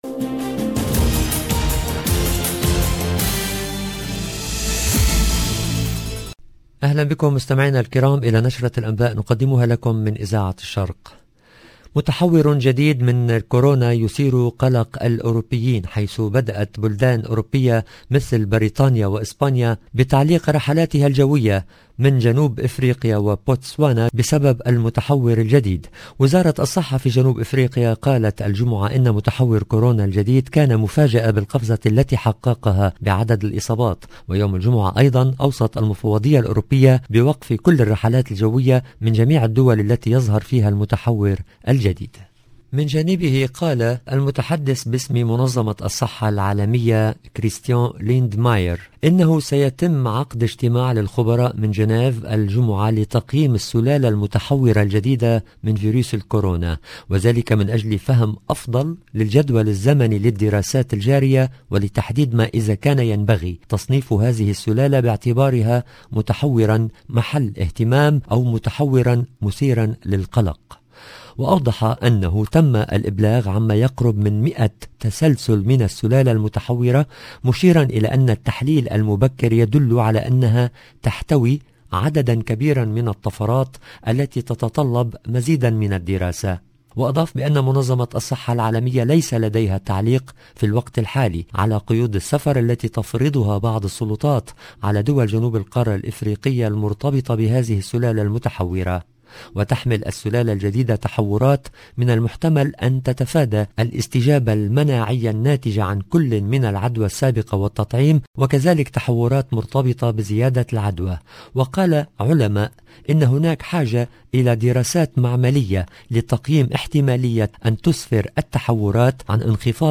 LE JOURNAL EN LANGUE ARABE DU SOIR DU 26/11/21